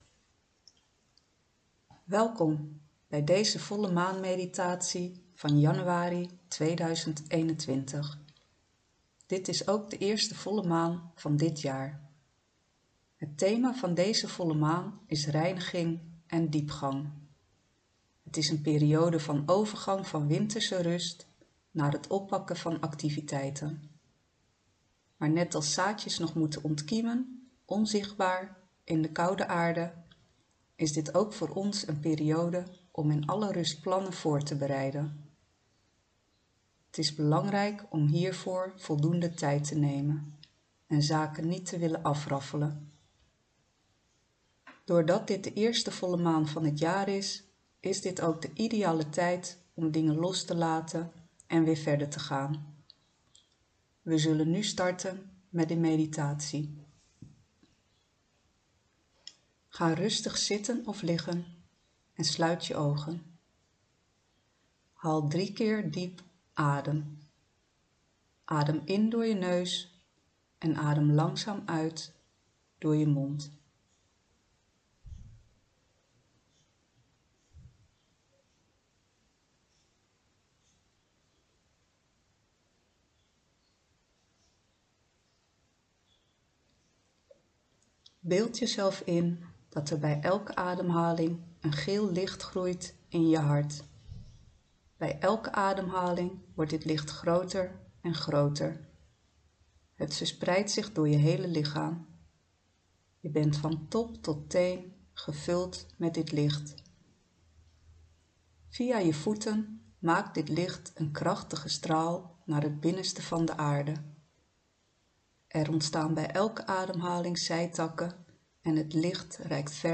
Volle maan meditatie januari 2021
Welkom bij deze volle maan meditatie van januari 2021.